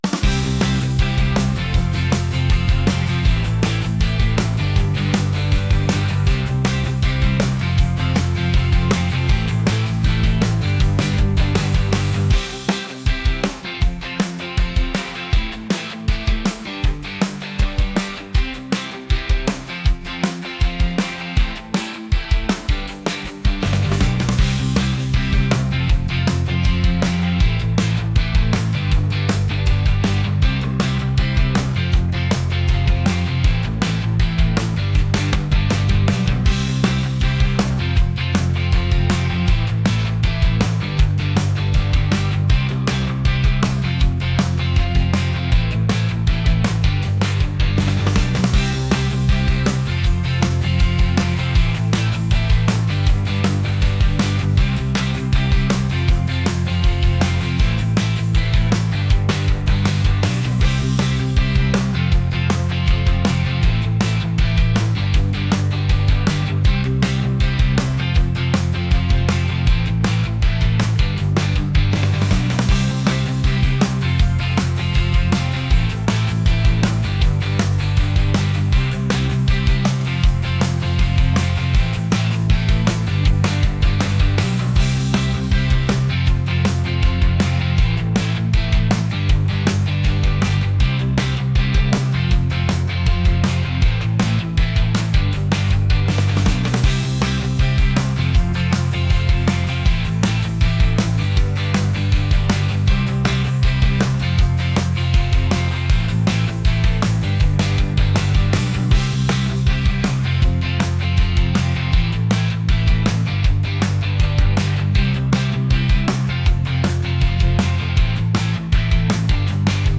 pop | upbeat | catchy